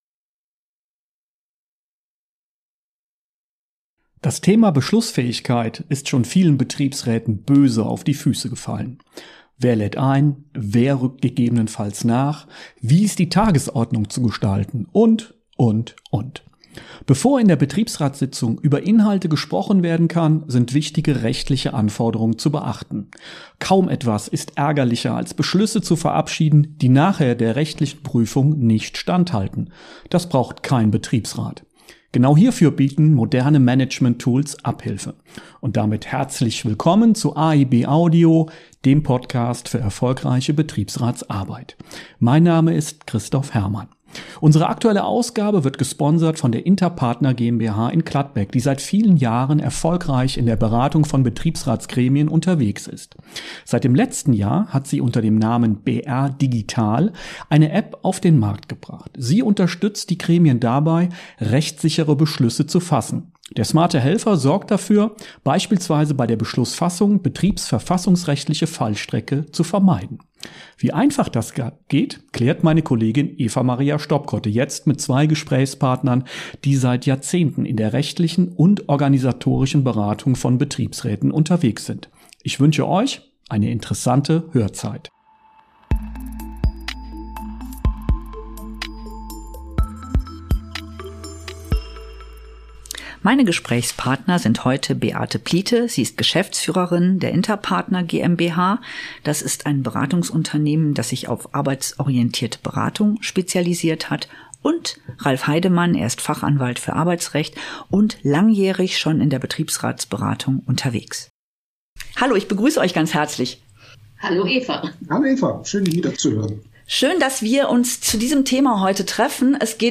… continue reading 64 jaksoa # Bildung # Bund-Verlag # Betriebsrat # Arbeitsrecht # Interviews # Recht # Für Betriebsräte